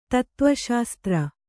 ♪ tattva śastra